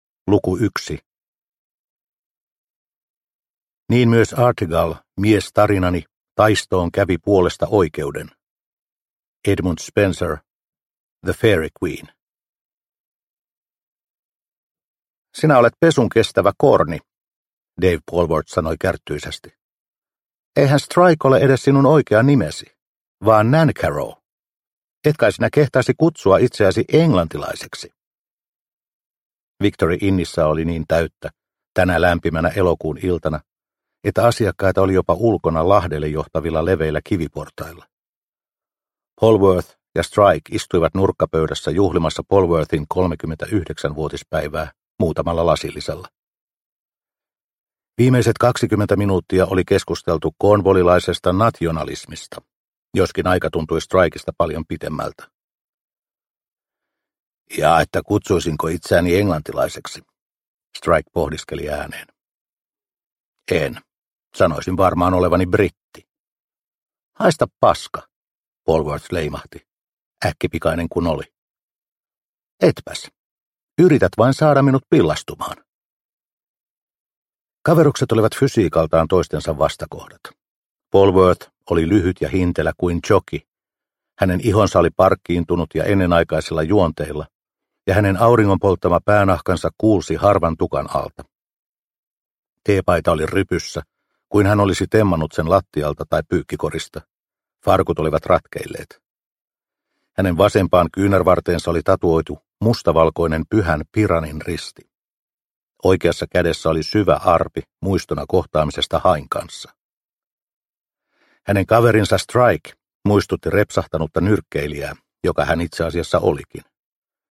Levoton veri – Ljudbok – Laddas ner